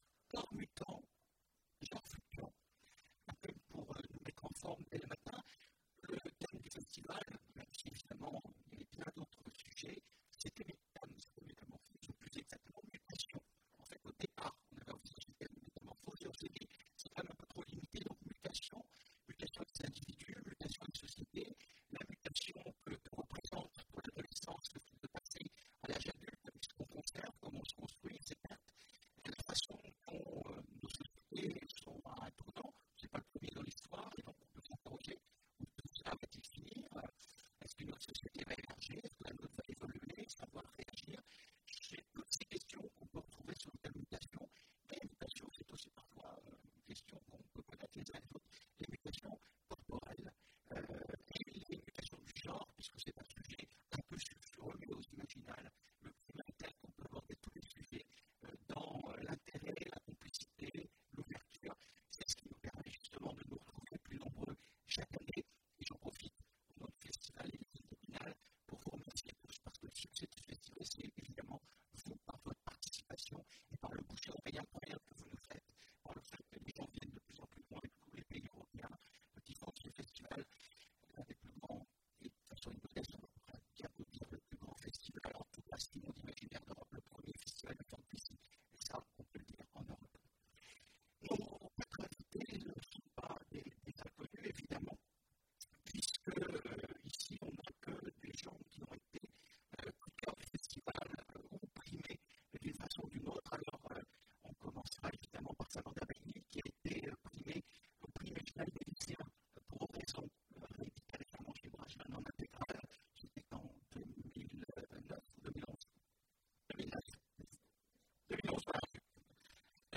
Imaginales 2015 : Conférence Corps mutants